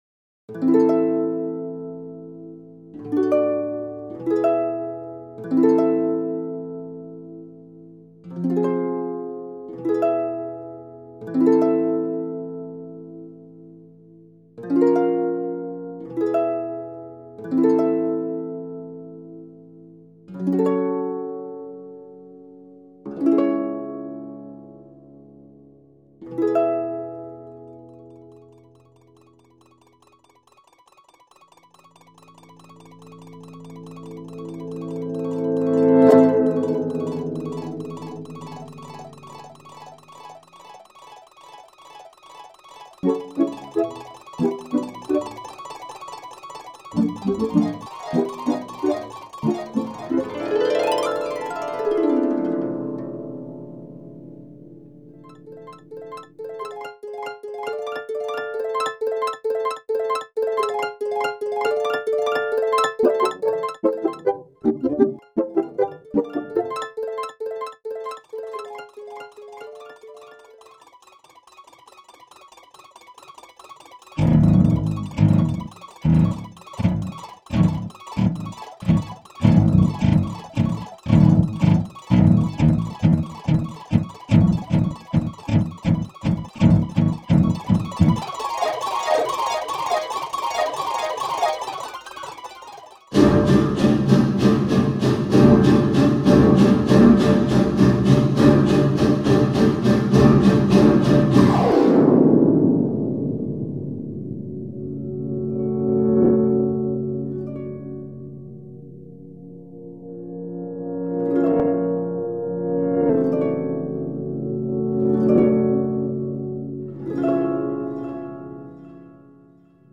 ELECTRONIC COMPUTER MUSIC